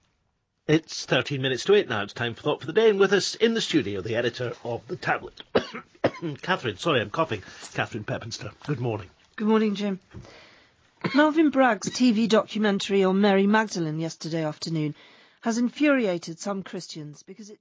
Jim Naughtie - cough